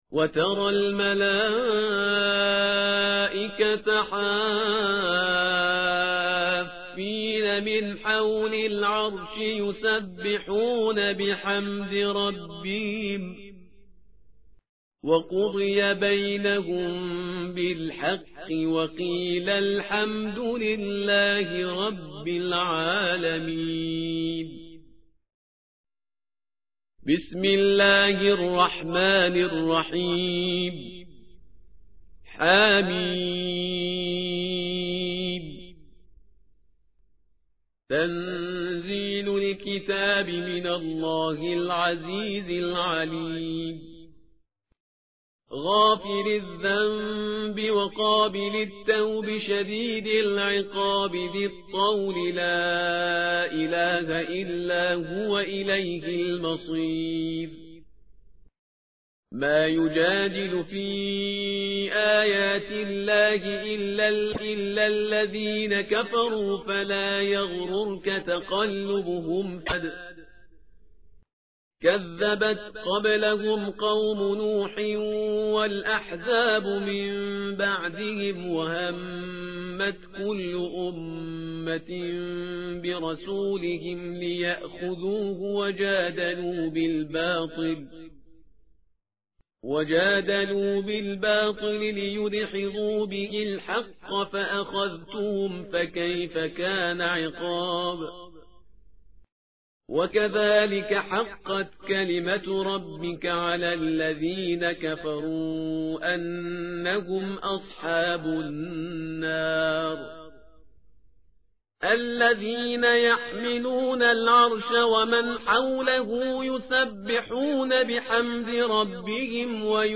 متن قرآن همراه باتلاوت قرآن و ترجمه
tartil_parhizgar_page_467.mp3